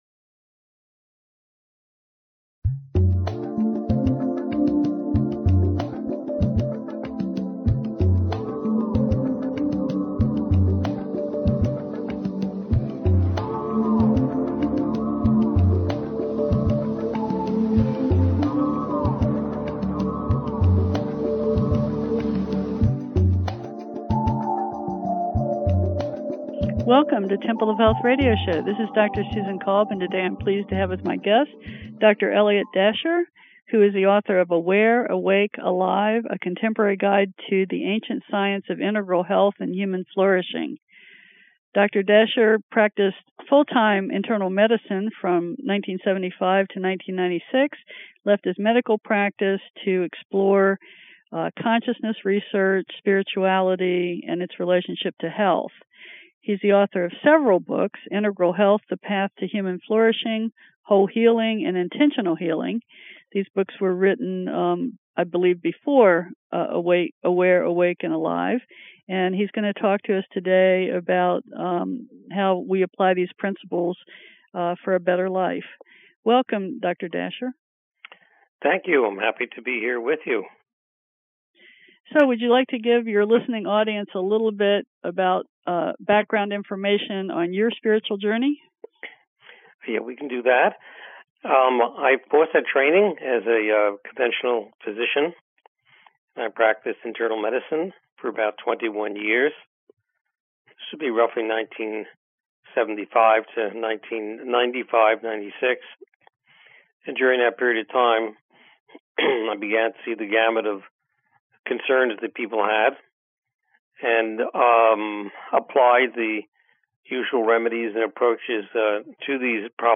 Temple of Health Radio Show